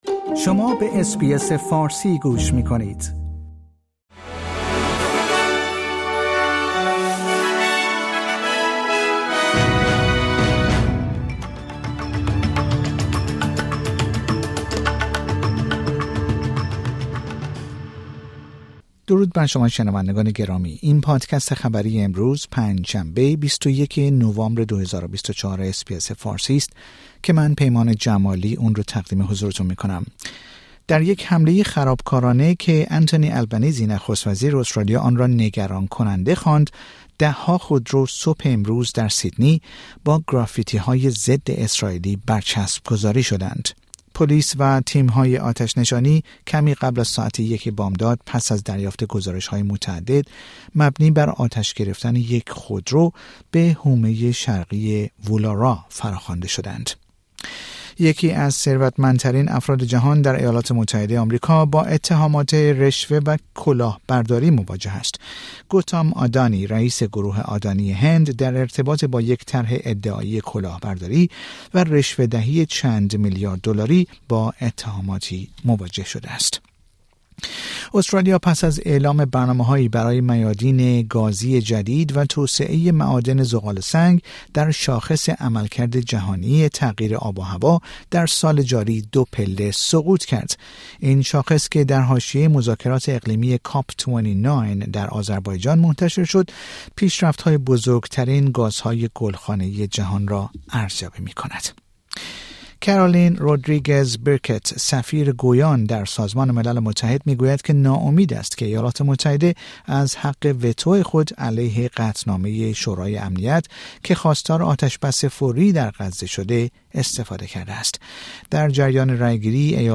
در این پادکست خبری مهمترین اخبار استرالیا در روز پنج شنبه ۲۱ نوامبر ۲۰۲۴ ارائه شده است.